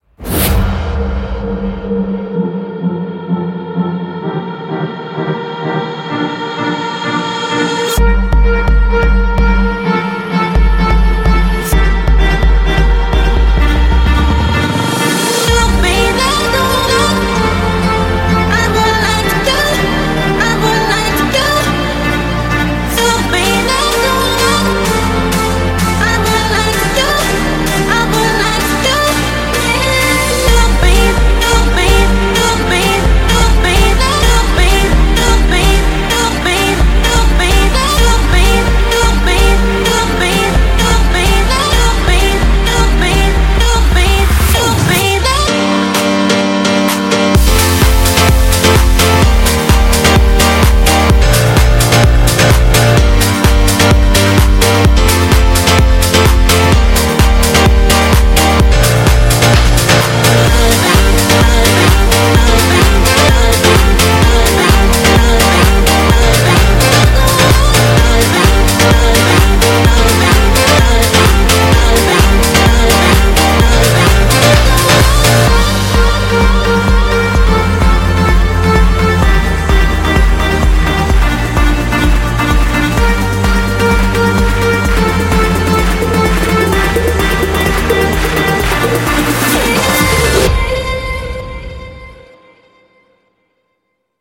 BPM128
CommentsA nice house song from 2014.